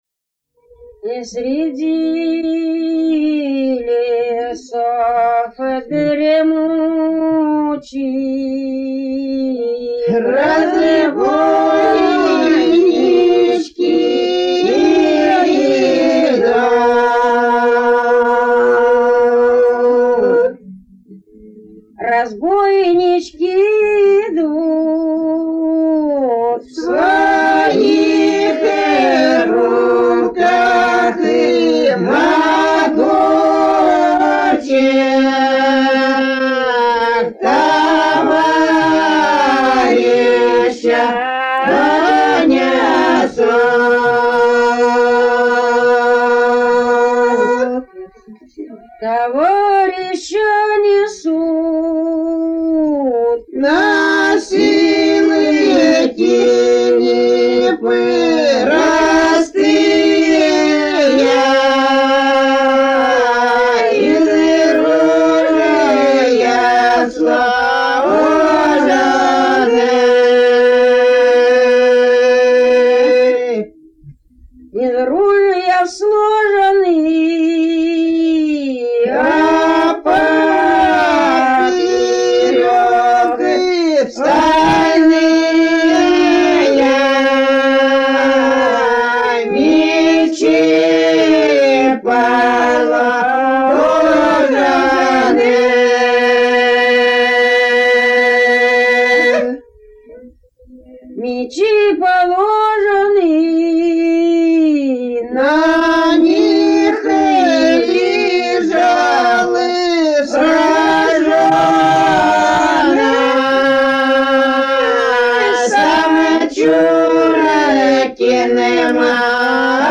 разбойничья